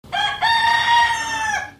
Крик петуха
Отличного качества, без посторонних шумов.
125_petuh.mp3